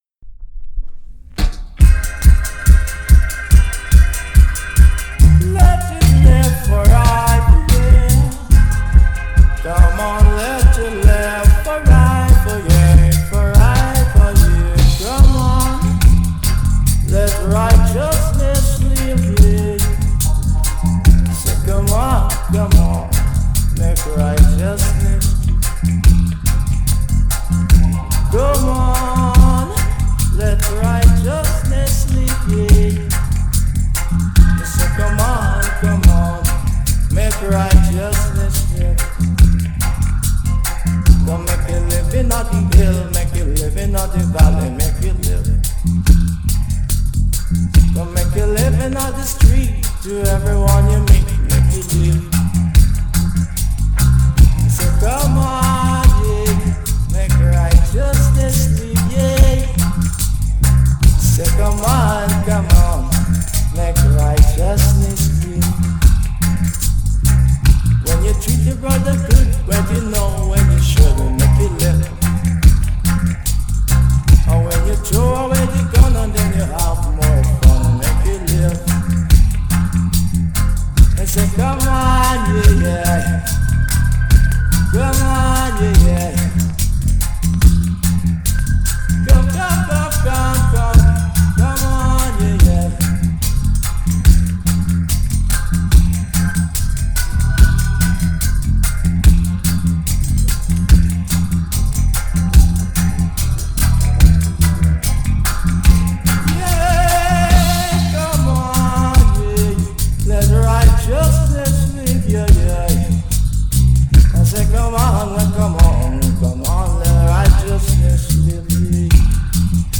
righteousness_dub.mp3